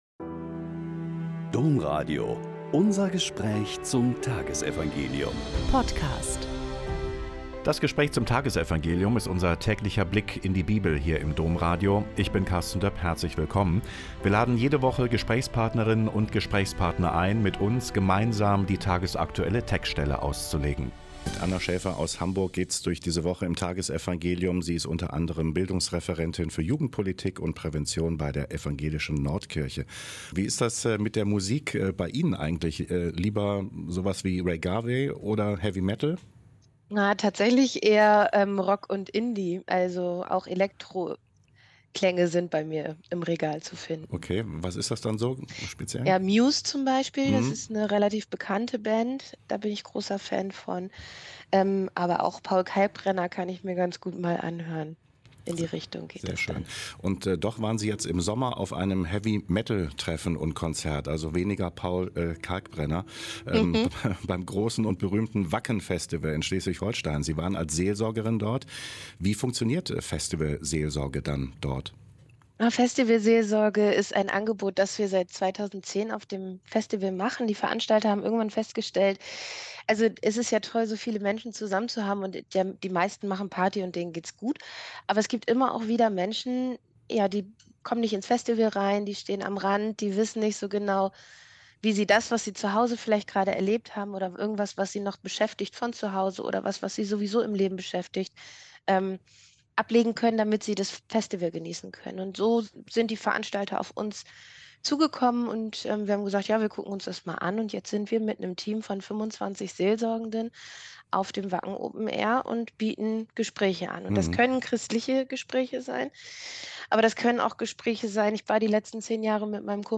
Lk 21,20-28 - Gespräch